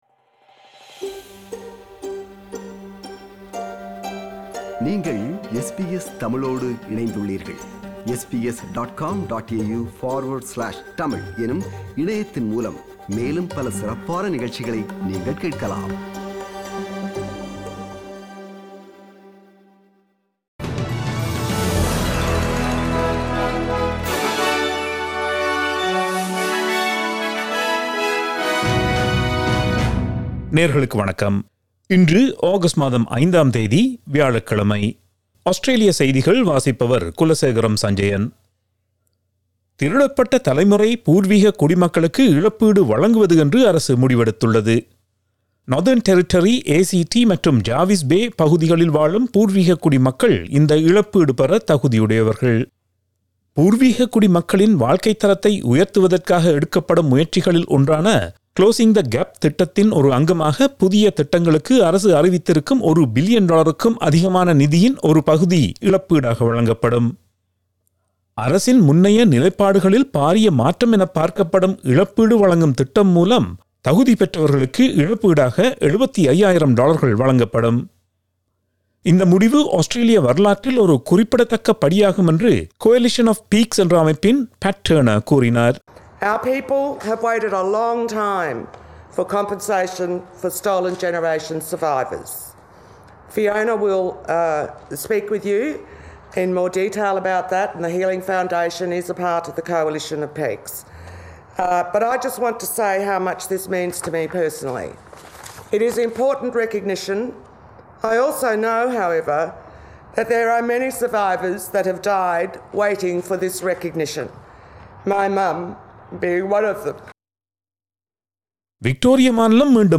Australian news bulletin for Thursday 05 August 2021.